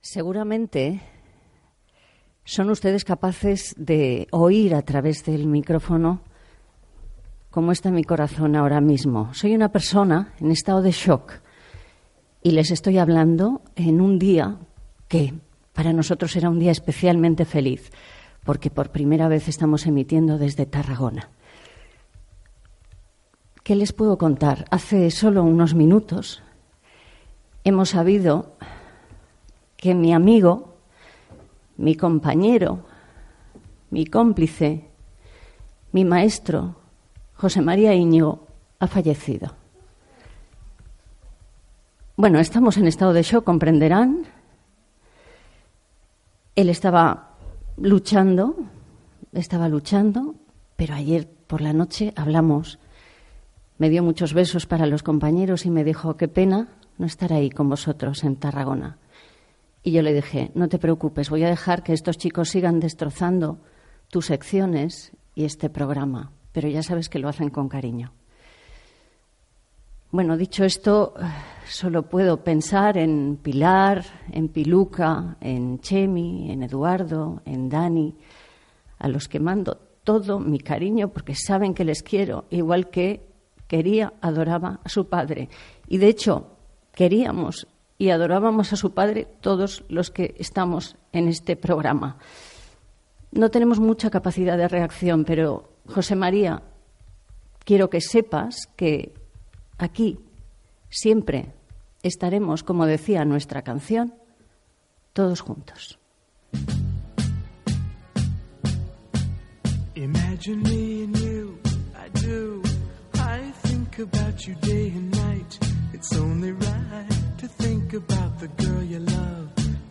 Programa, fet des de Tarragona, el dia després de la mort del periodista i col·laborador del programa José María Íñigo.